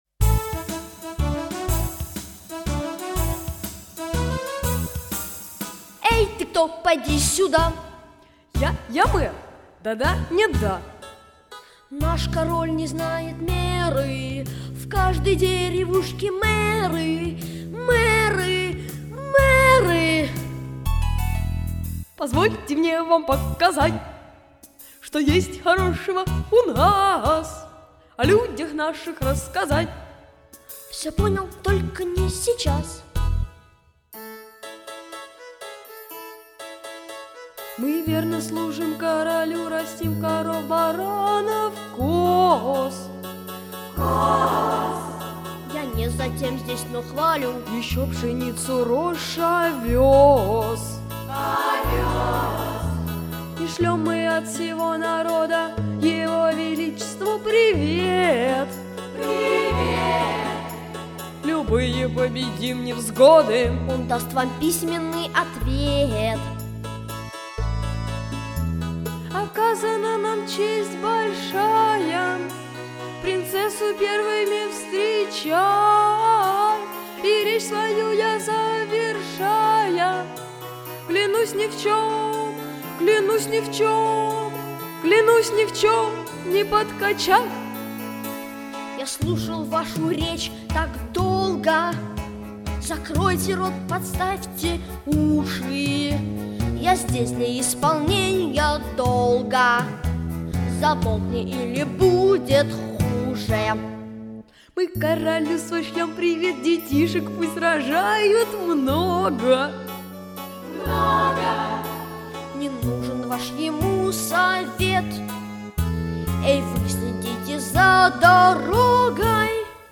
Спектакль
февраль 1998, Гимназия №45